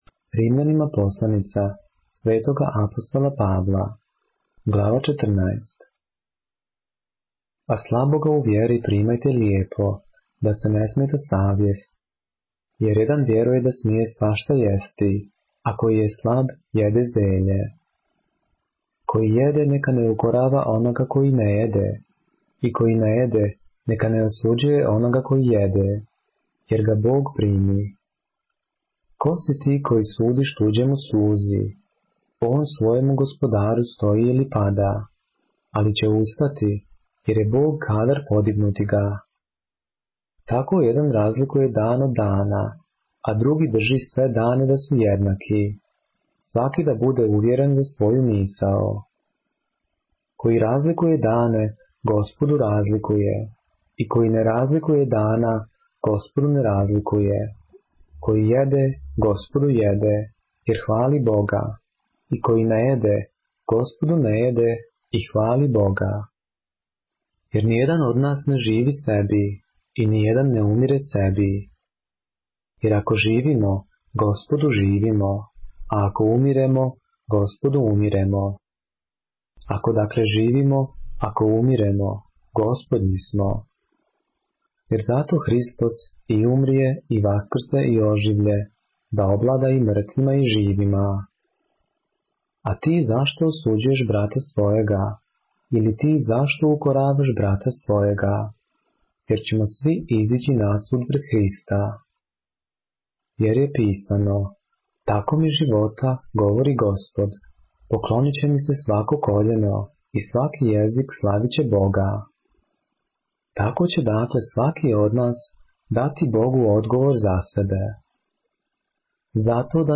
поглавље српске Библије - са аудио нарације - Romans, chapter 14 of the Holy Bible in the Serbian language